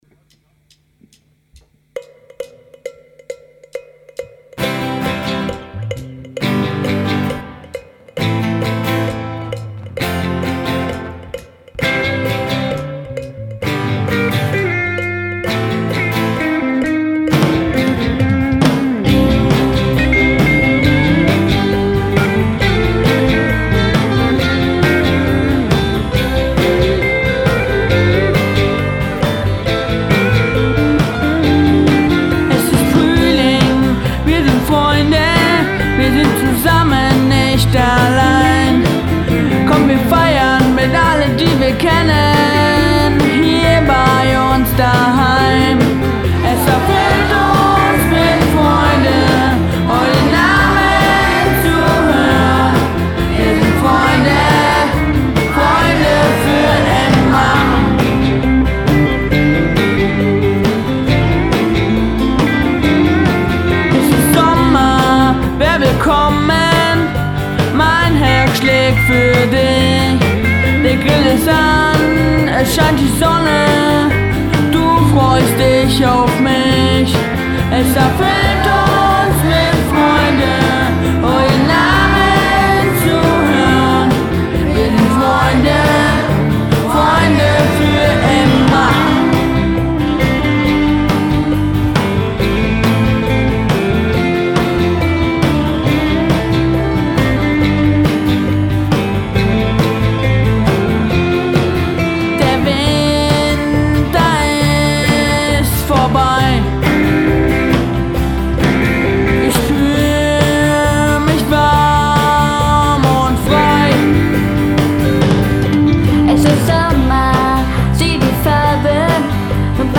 die neue Chor-CD ist da!
einem mitreißenden Hit
nun zum ersten Mal zu hören in deutscher Übersetzung.